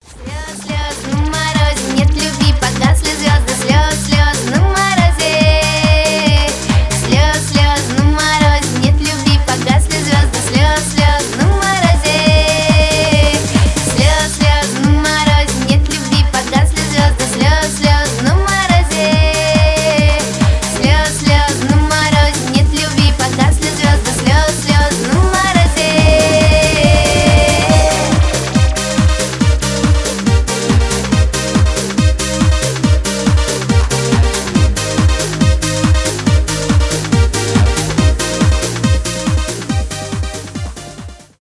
евродэнс
танцевальные